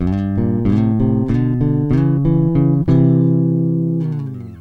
thirds.mp3